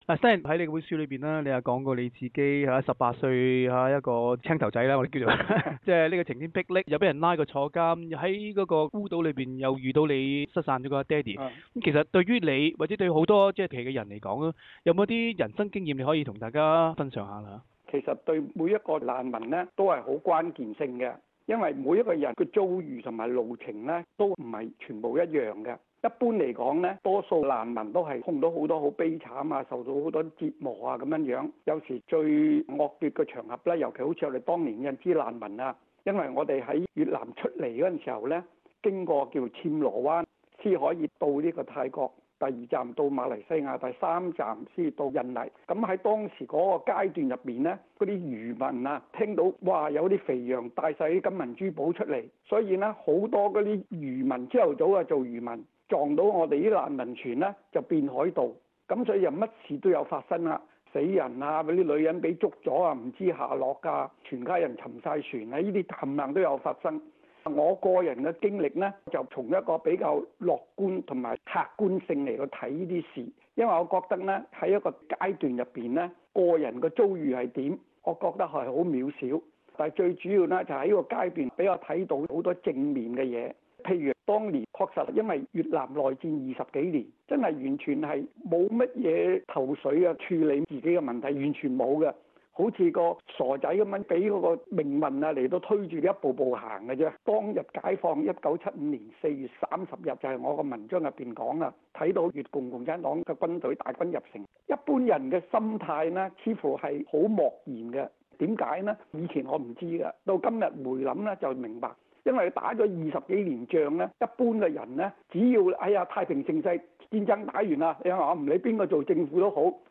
【社区专访】